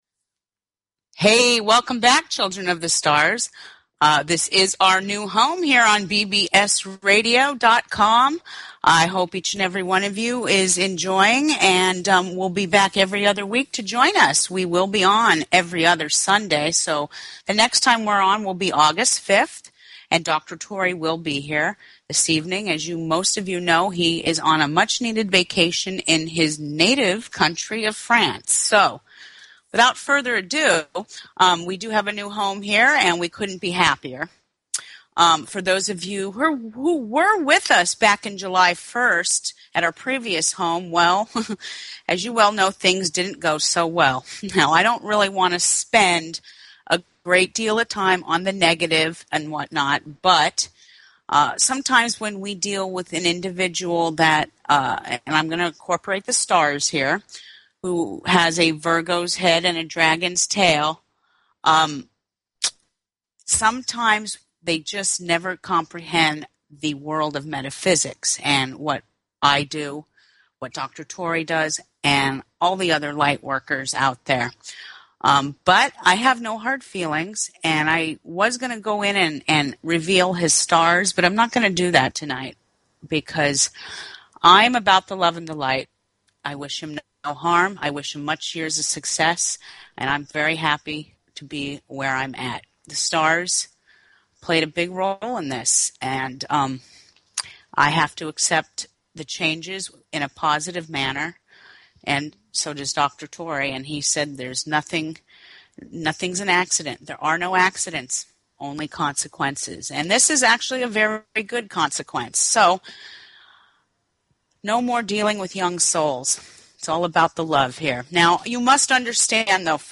Talk Show Episode, Audio Podcast, Cosmic_Radio and Courtesy of BBS Radio on , show guests , about , categorized as